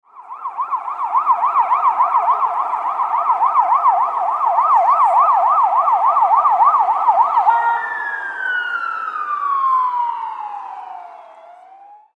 Alarm-noises.mp3